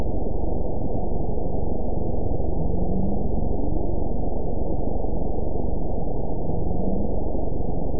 event 911519 date 03/03/22 time 03:55:38 GMT (3 years, 2 months ago) score 7.35 location TSS-AB05 detected by nrw target species NRW annotations +NRW Spectrogram: Frequency (kHz) vs. Time (s) audio not available .wav